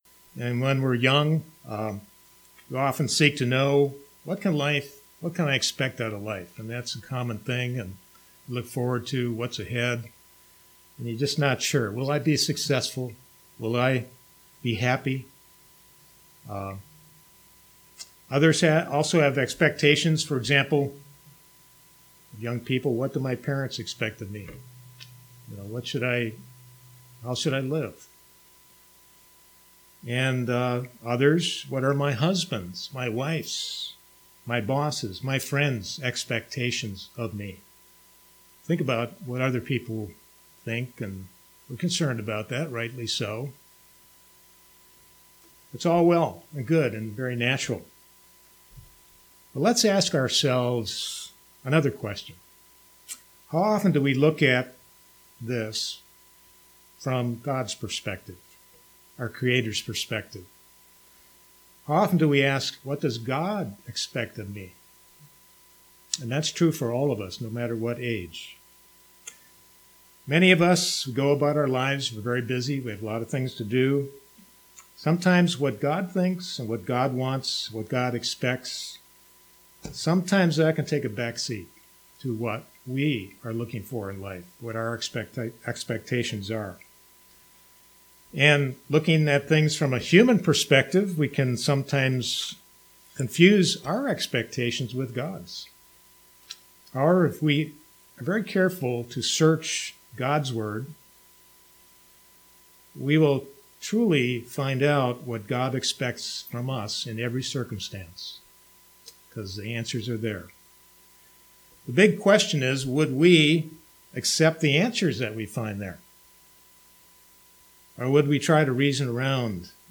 Sermons
Given in Knoxville, TN